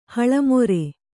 ♪ haḷa more